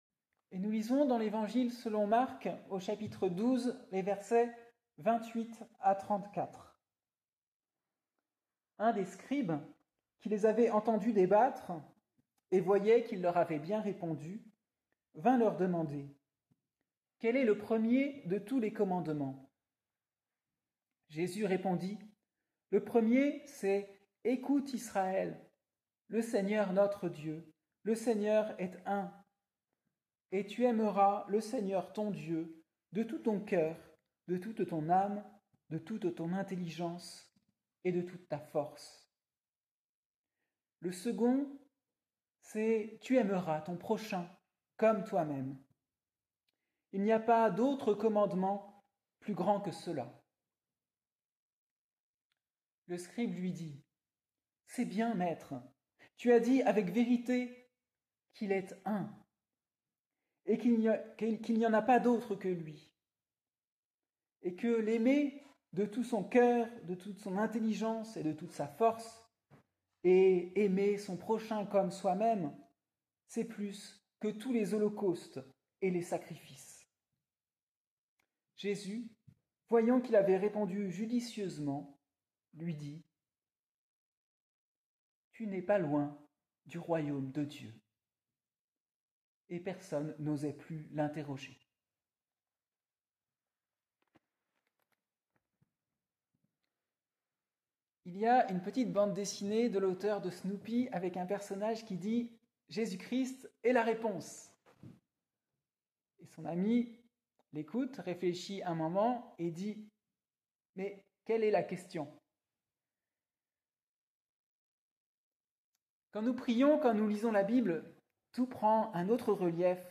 Prédication du dimanche 3 novembre 2024